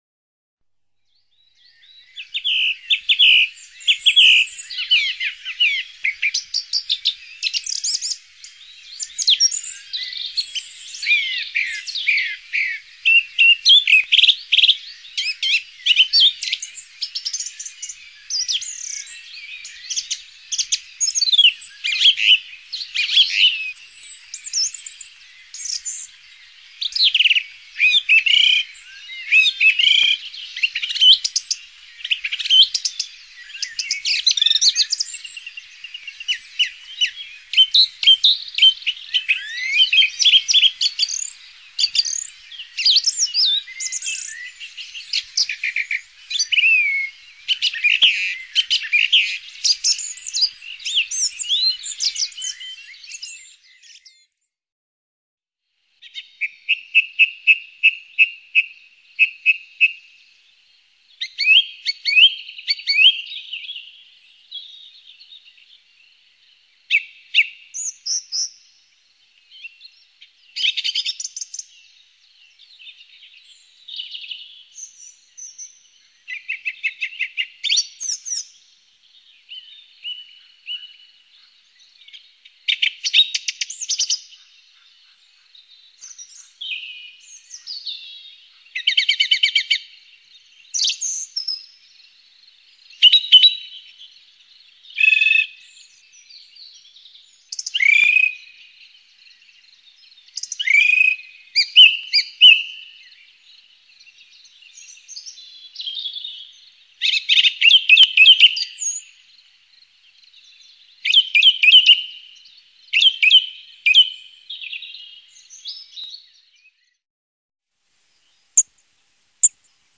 Hier après-midi, petit tour dans les bois de "La Chapelle" vers Cauroy accompagné par les premiers chants de
Grives musiciennes et le pépiement de quelques Mésanges à longue queue.
Grive musicienne.mp3